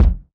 Kick Zion 5.wav